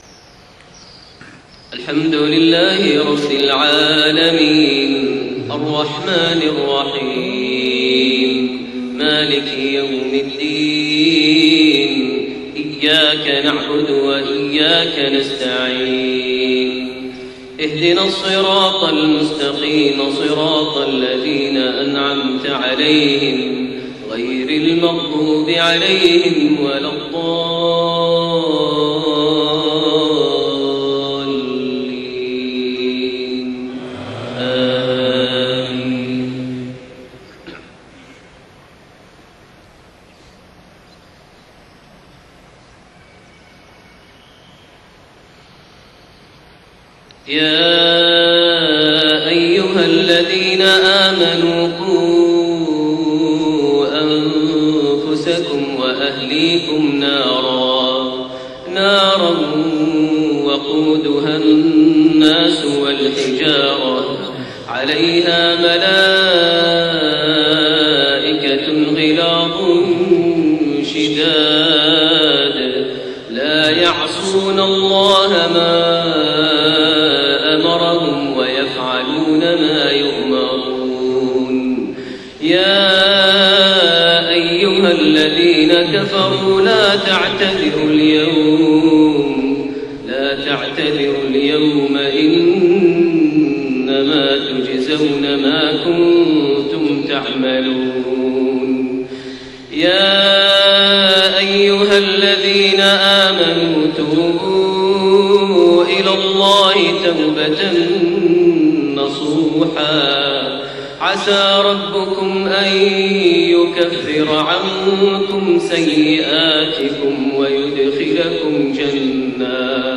صلاة المغرب3-3-1432 من سورة التحريم6-12 > 1432 هـ > الفروض - تلاوات ماهر المعيقلي